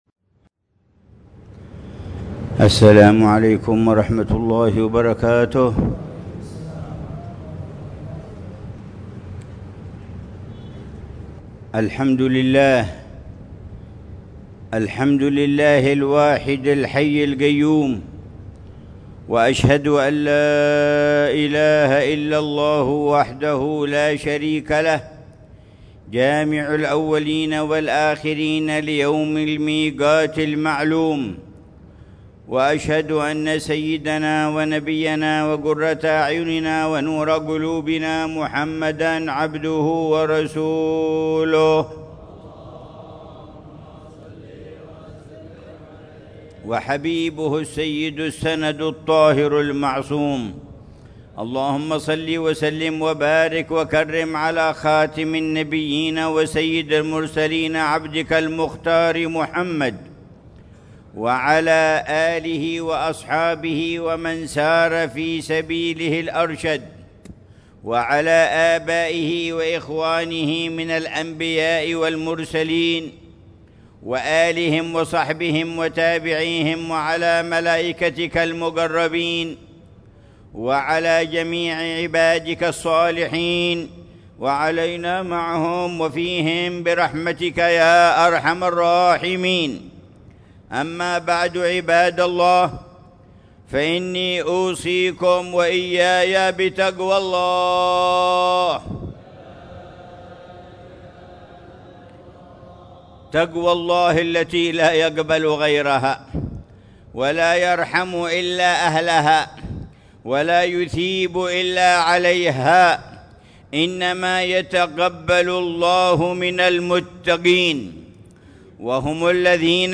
خطبة الجمعة للعلامة الحبيب عمر بن محمد بن حفيظ في جامع الروضة بعيديد، مدينة تريم، 23 محرم 1447هـ بعنوان: